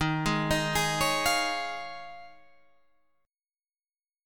Eb9sus4 chord